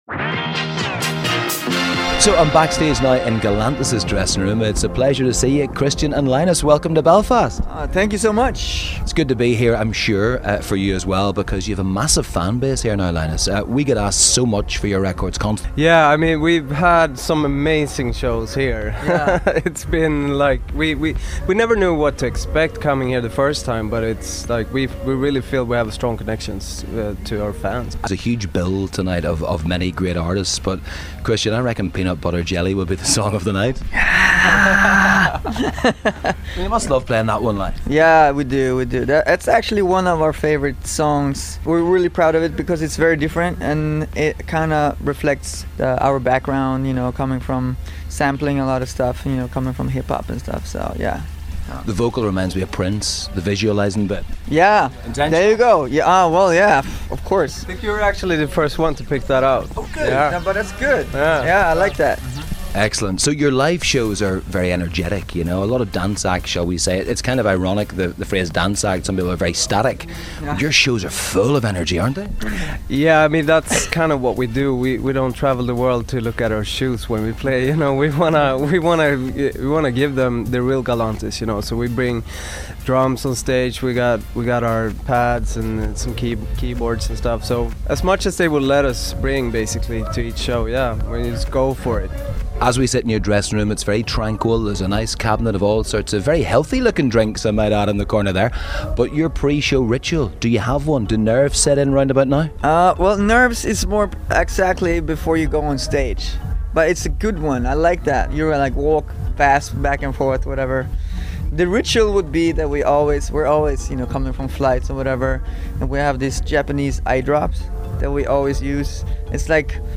catches up with Galantis backstage at Tennent's Vital